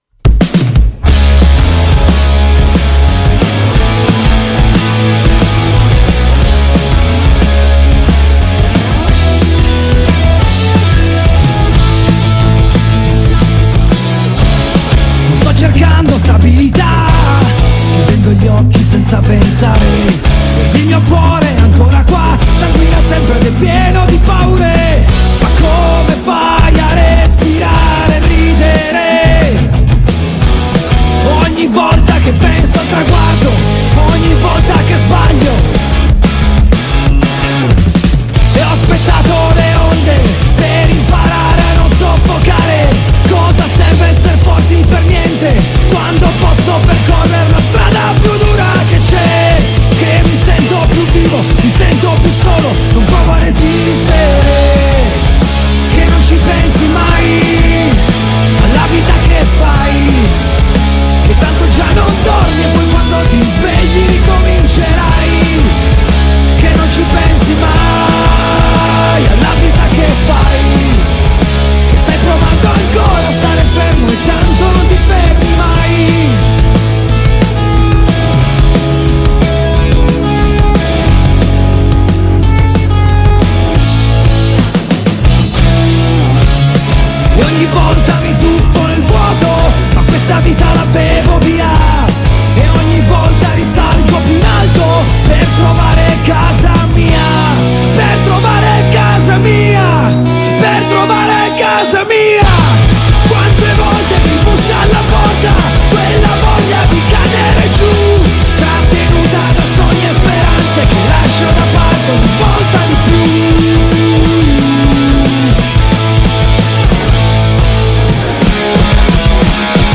La strada più dura che c’è INTERVISTA AI MADBEAT Madbeat